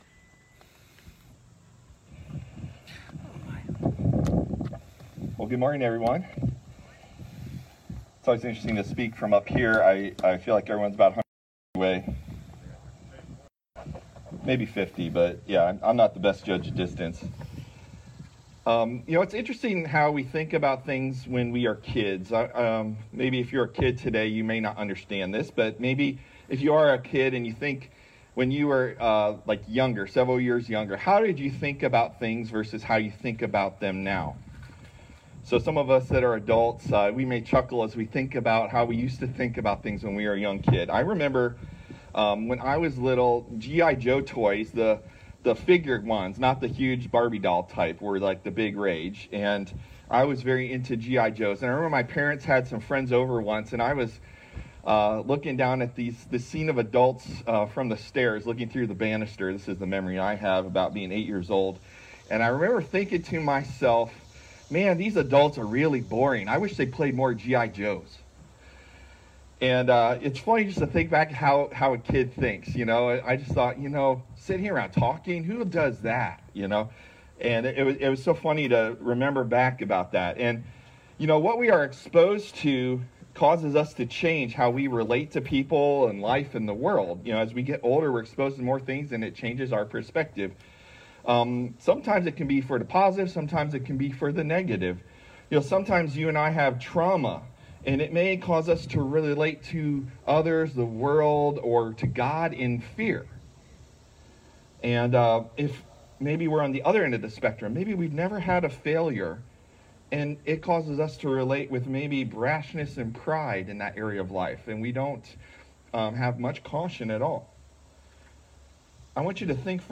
Due to a technical difficulty, today's sermon was split over two recordings.